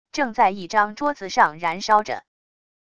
正在一张桌子上燃烧着wav音频